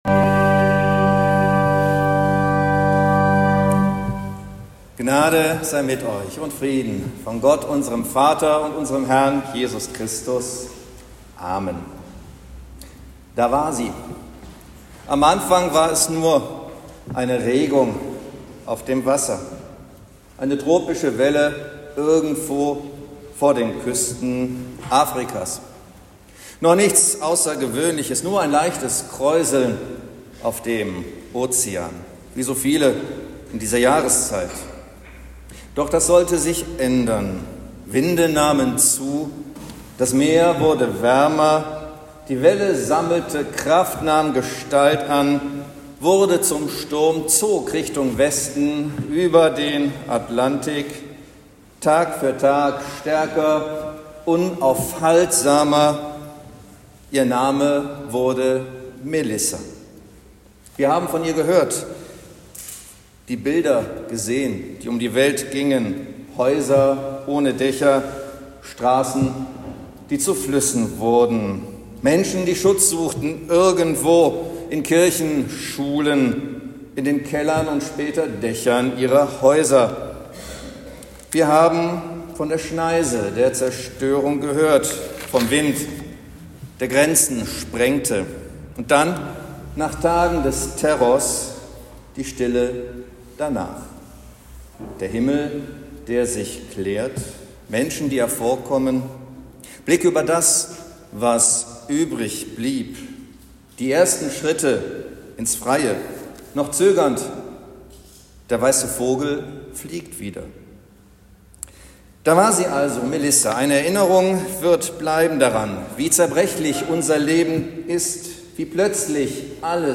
Predigt zum 20. Sonntag nach Trinitatis